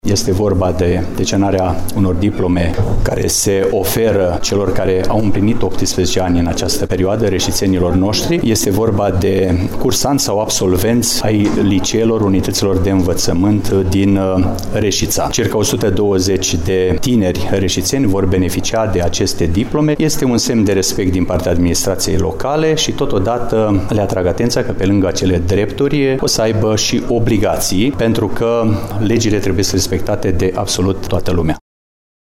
Primarul municipiului Reşiţa, Mihai Stepanescu: